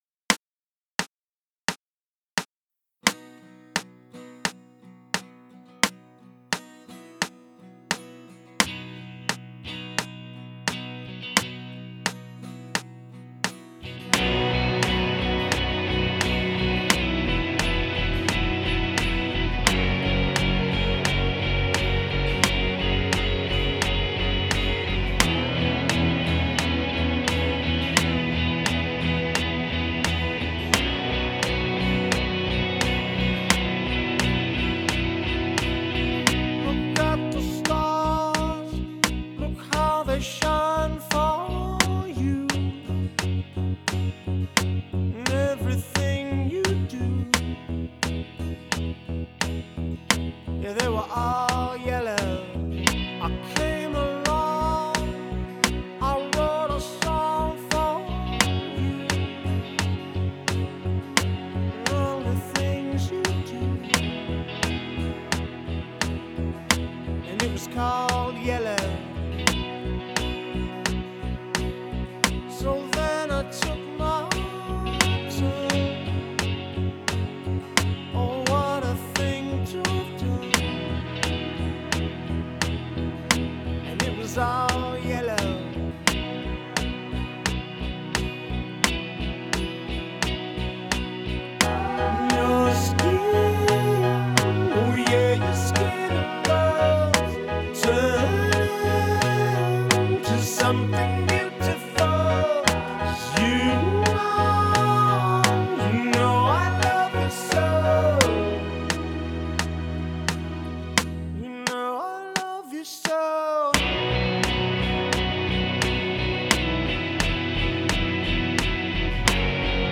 Playalong